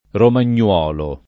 romagnuolo [ roman’n’ U0 lo ] → romagnolo